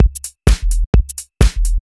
Electrohouse Loop 128 BPM (21).wav